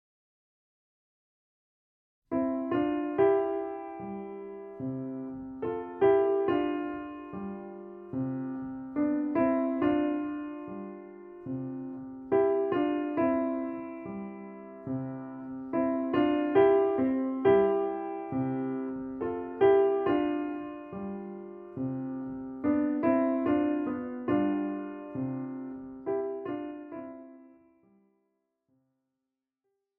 41 leichte Klavierstücke
Besetzung: Klavier